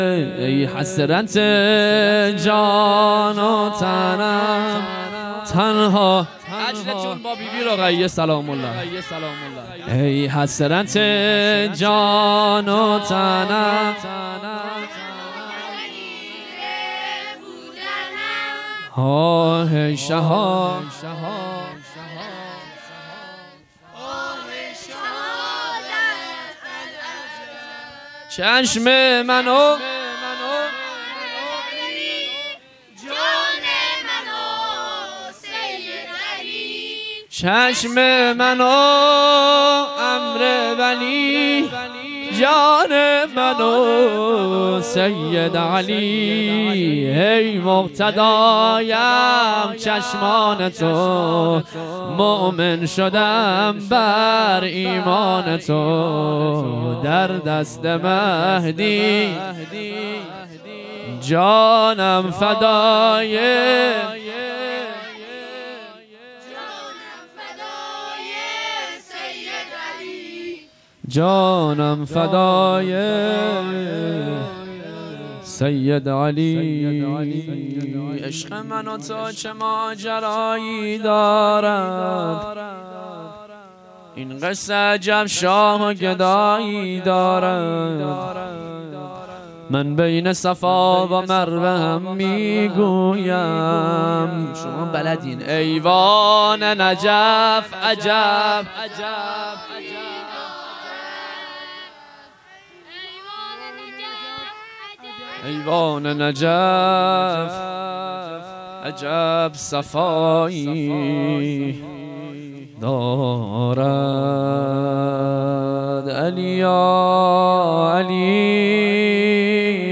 محرم 1442 شب چهارم